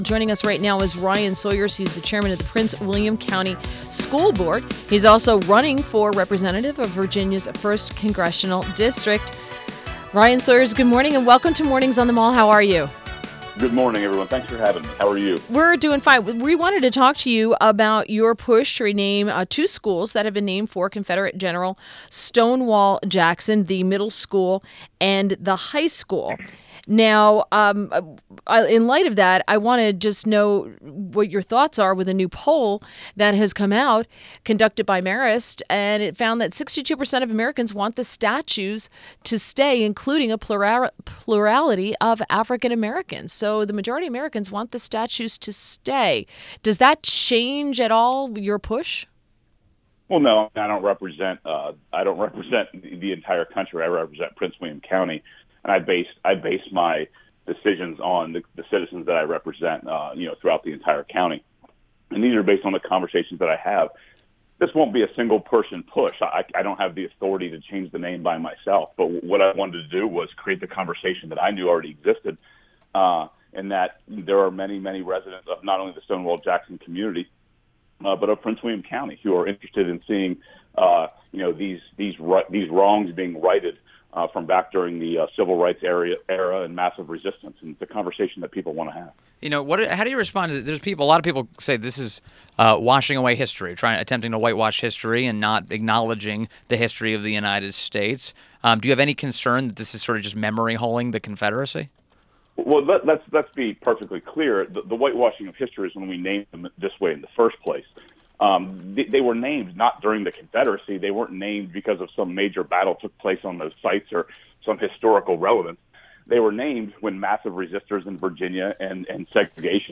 WMAL Interview - RYAN SAWYERS - 08.18.17
INTERVIEW — RYAN SAWYERS – Chairman of the Prince William County School Board and running for Representative of Virginia’s 1st Congressional District (Rep. Rob Wittman’s district)